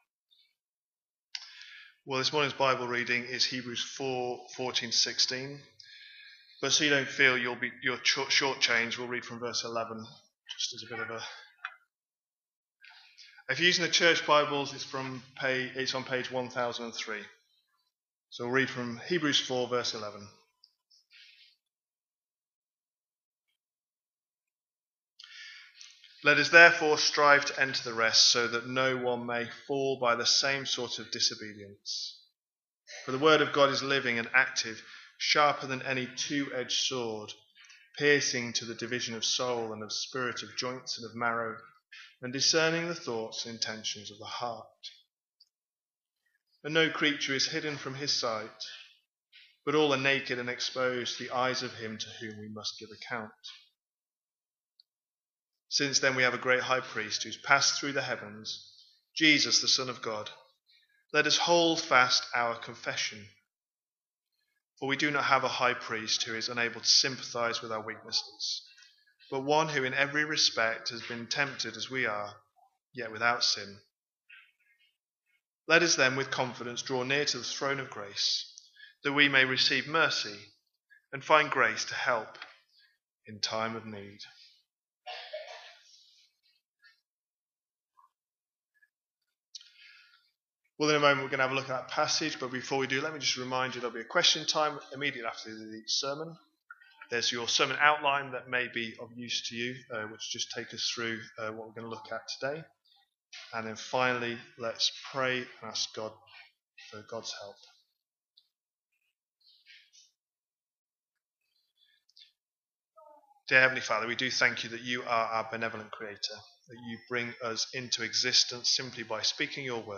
A sermon preached on 17th November, 2024, as part of our Hebrews 24/25 series.